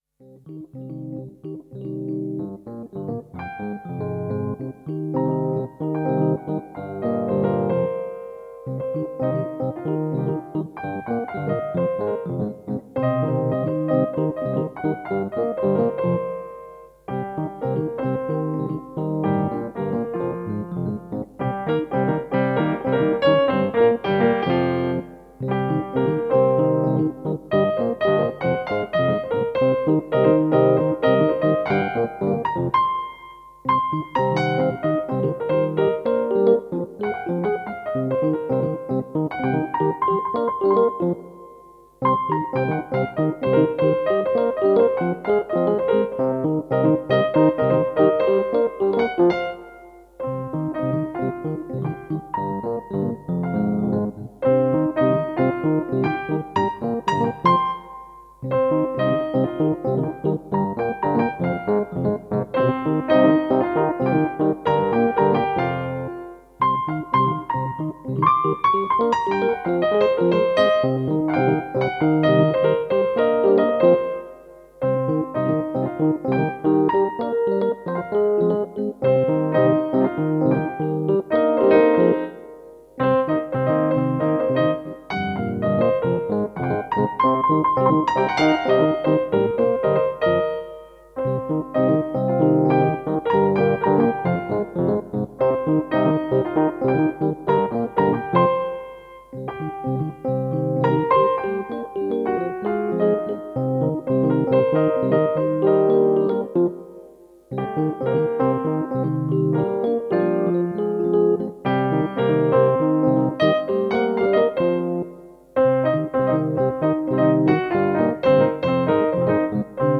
Jazziger fröhlicher Piano Latin. Piano mit E-Piano.
Tempo: 58 bpm / Datum: 09.10.2017
Jazz/Creative Commons License 4.0 / noncommercial use free